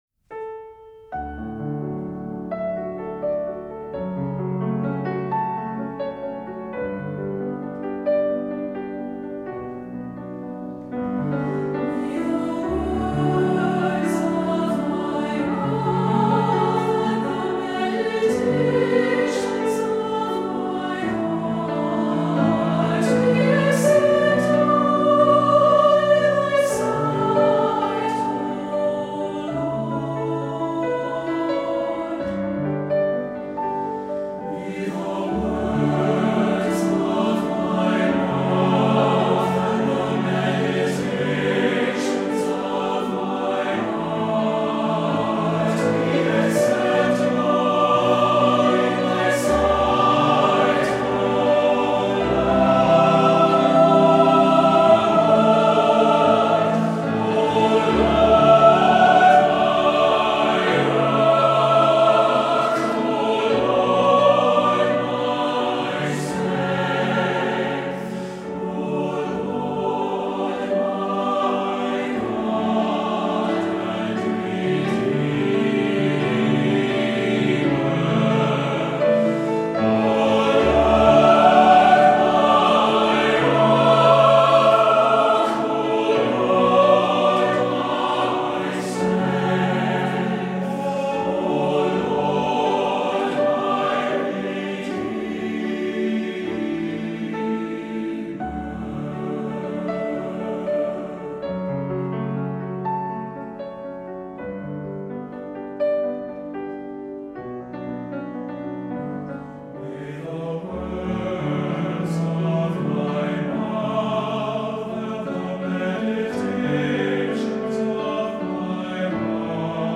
Voicing: SATB and Piano